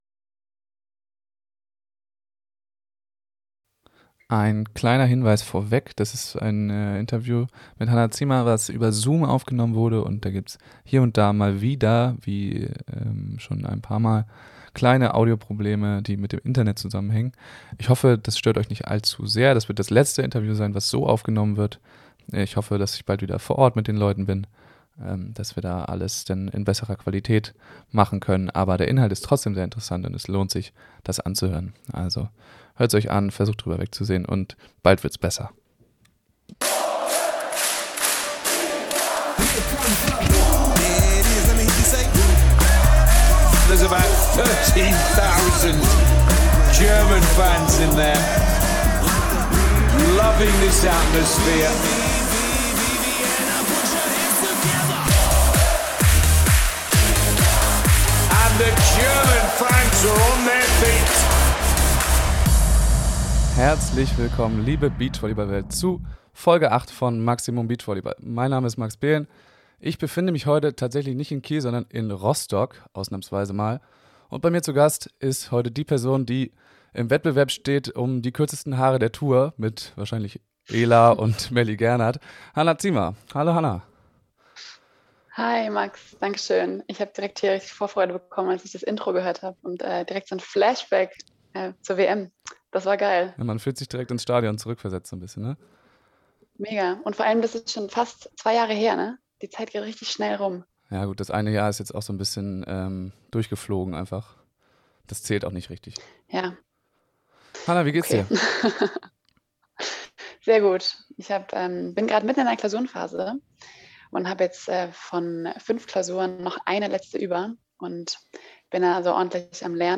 Durch den Plauderton ging das Gespräch ziemlich schnell vorüber, es lohnt sich!